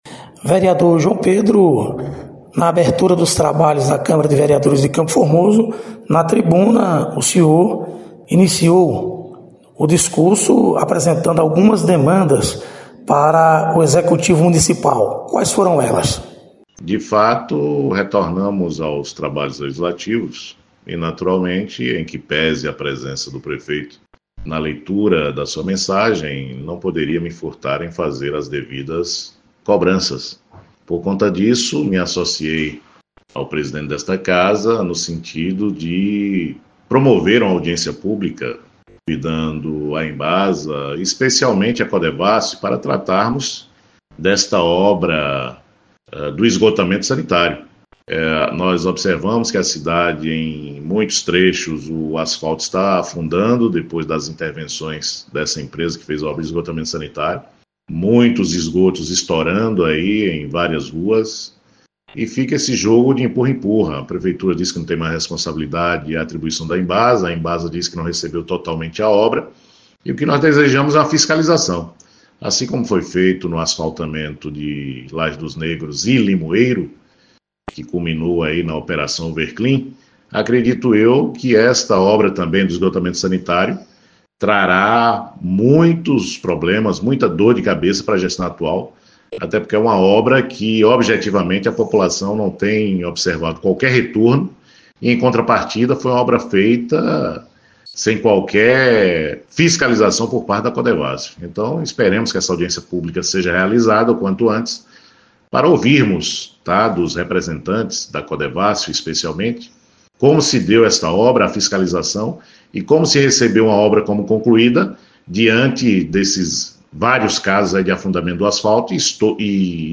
Vereadores de Campo Formoso – Sessão de abertura dos trabalhos legislativos.